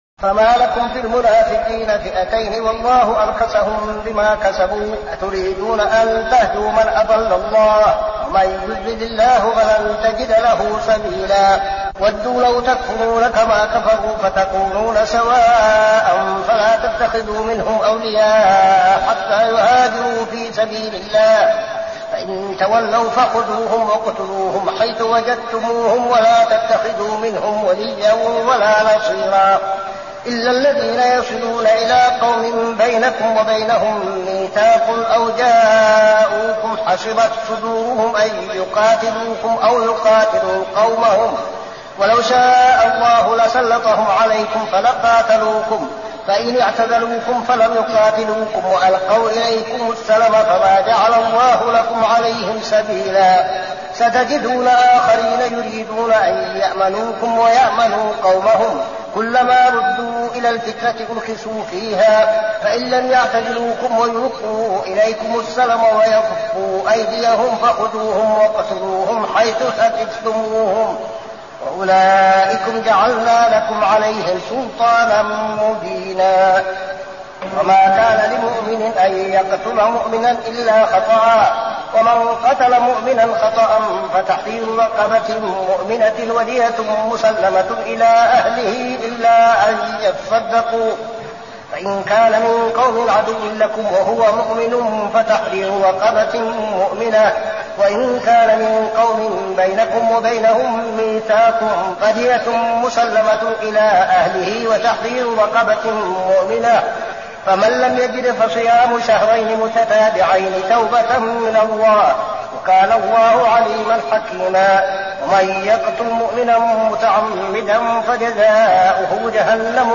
صلاة التراويح ليلة 6-9-1402هـ سورة النساء 88-147 | Tarawih prayer Surah An-Nisa > تراويح الحرم النبوي عام 1402 🕌 > التراويح - تلاوات الحرمين